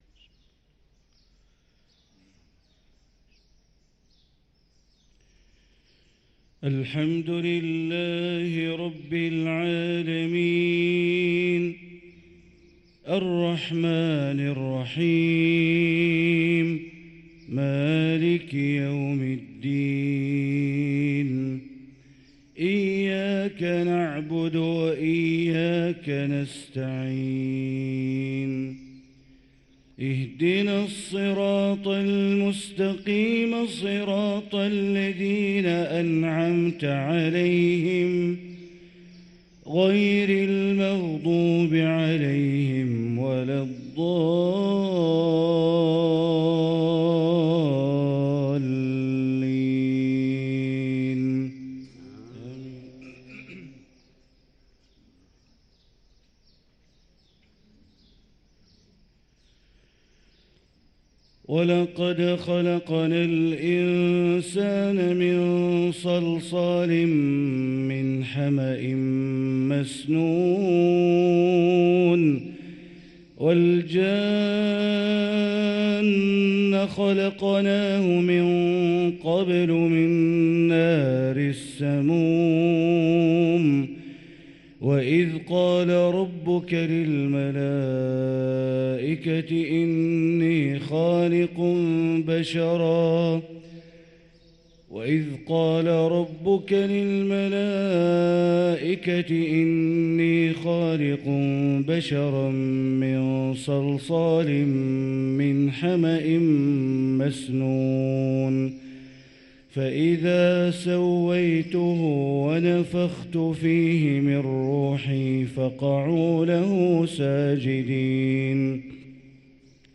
صلاة الفجر للقارئ بندر بليلة 8 جمادي الآخر 1445 هـ
تِلَاوَات الْحَرَمَيْن .